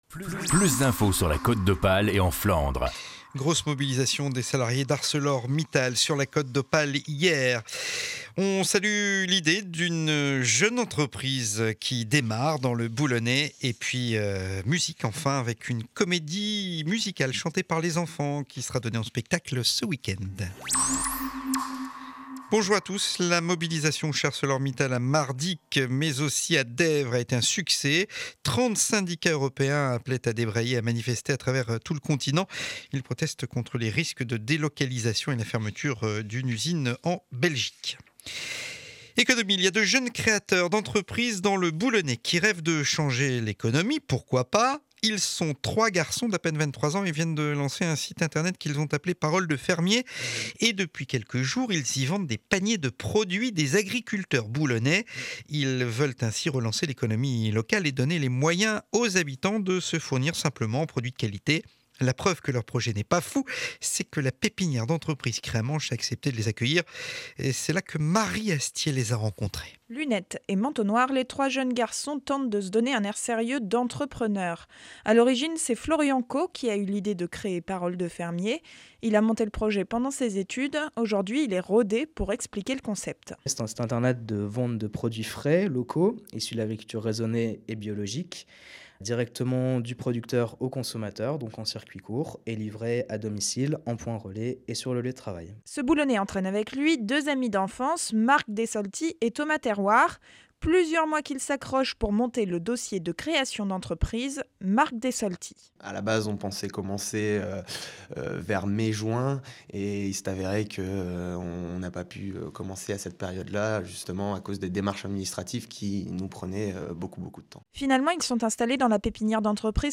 Journal de 12h00 du jeudi 8 décembre édition de Boulogne.